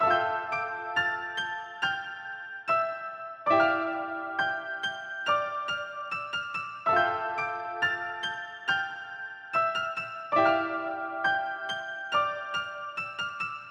白色的云朵 140 BPM A小调
描述：用FL Studio和Nexus三角钢琴制作的平静的钢琴循环。
Tag: 140 bpm Classical Loops Piano Loops 2.31 MB wav Key : Unknown